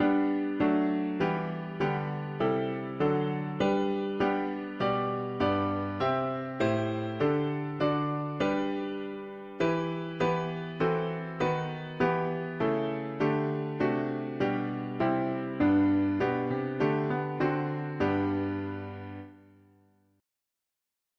Key: G major
4part chords